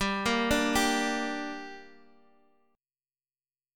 Listen to Gm strummed